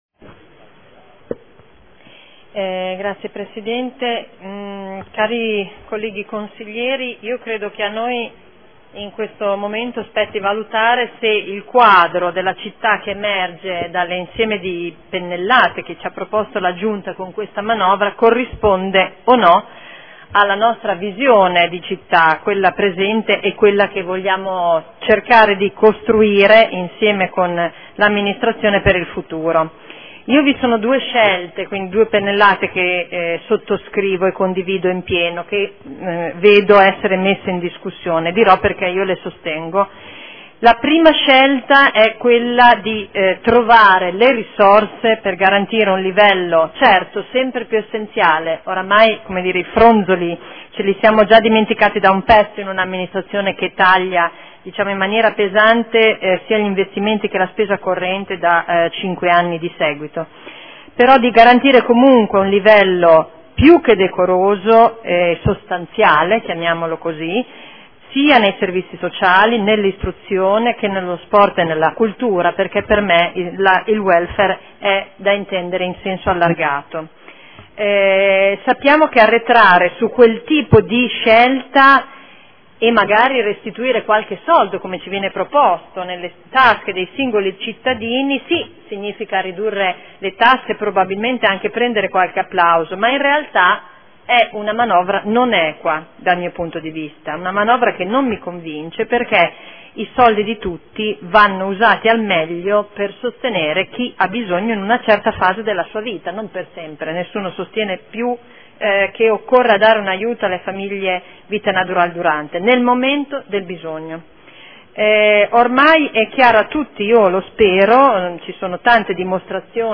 Seduta del 05/03/2015 Dibattito sul Bilancio, sulle delibere, odg ed emendamenti collegati